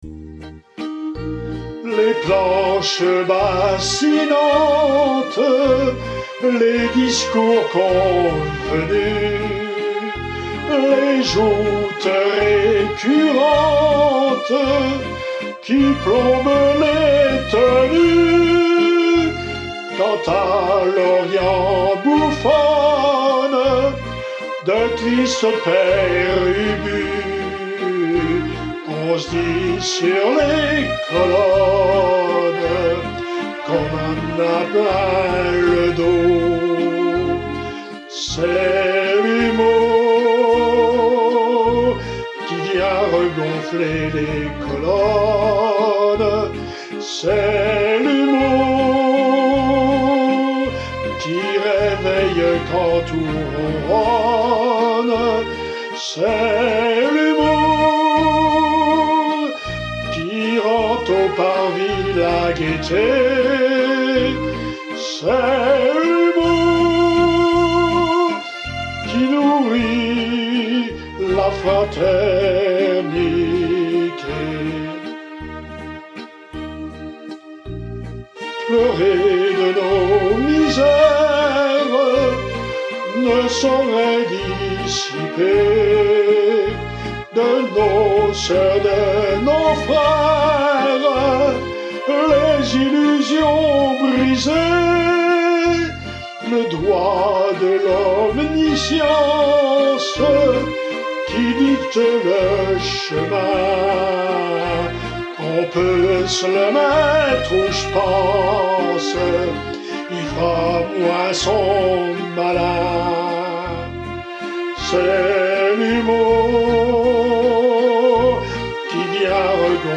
Chansons maçonneuses                                           Suivante
Sur l’air de “c’est l’amour”, valse de l’Opéra comique “Les Saltimbanques”